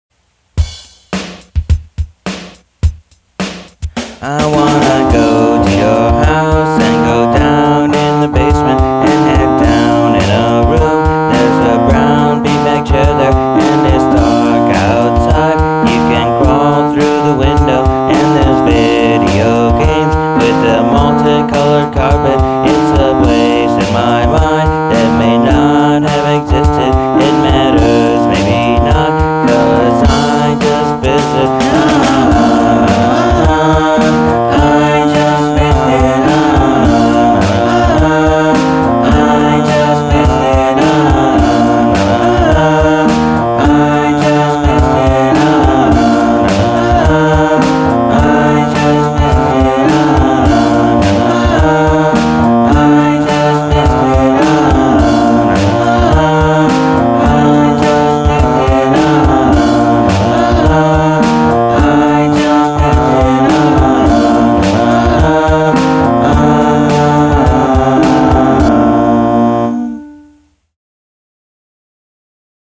(Super Hi-Fi, Studio Produced Sellout Quality)